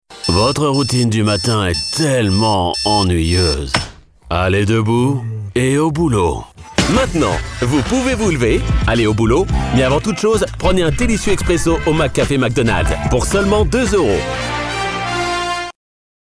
Hire Promos Voice Actors For Your Project
Articulate
Believable
Calm